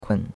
kun3.mp3